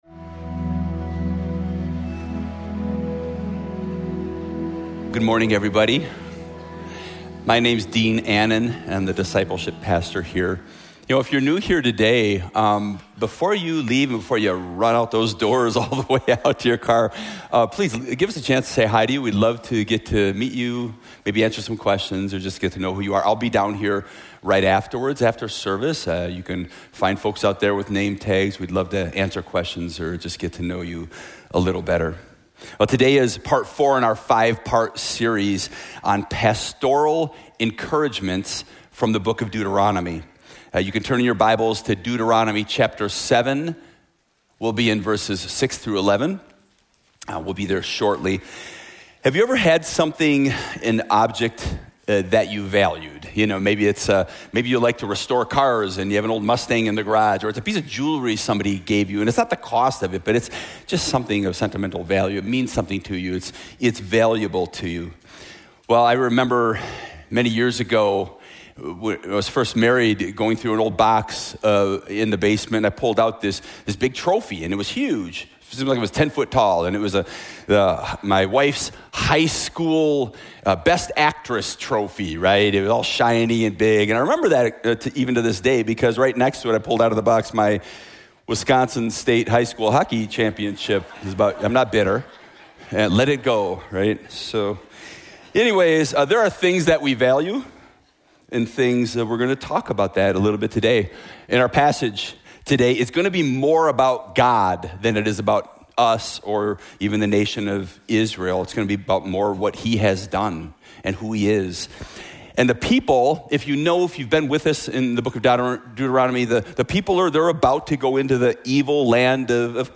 Village Church of Bartlett: Sermons